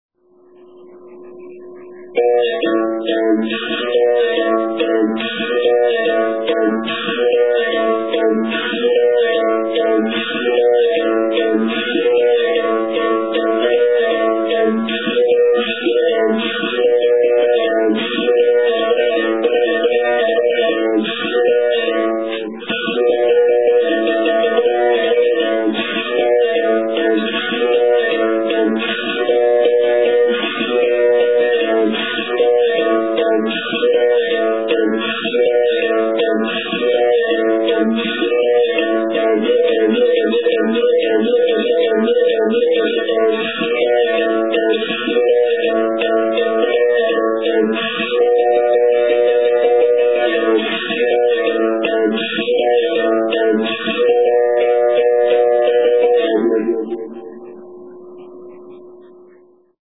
Совершая удары бакетой по струне и периодически прижимая монету к последней, беримбау может издавать три основных звука: открытый (тон), закрытый (тин) и жужжащий звук слегка прижатой к струне монеты (чи).
Сао Бенто Гранди ди Ангола (Режиональ)